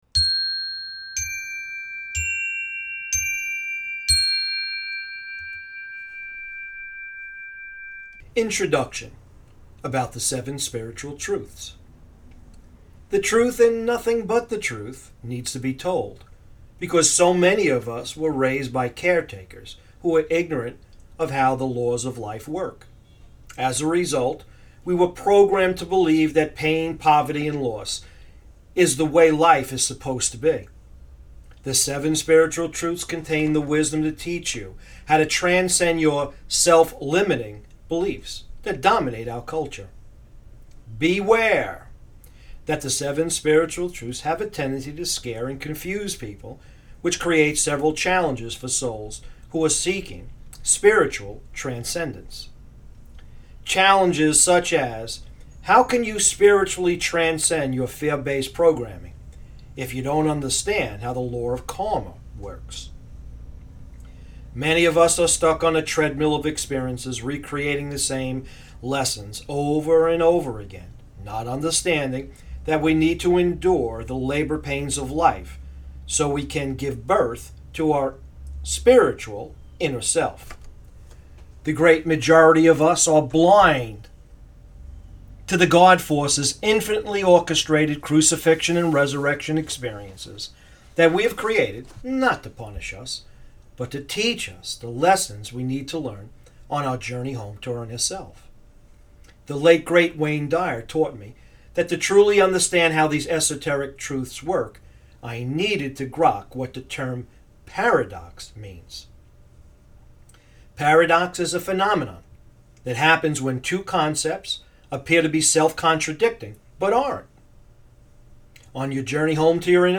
Seven Spiritual Truths Audiobook Icon